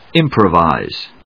音節im・pro・vise 発音記号・読み方
/ímprəvὰɪz(米国英語)/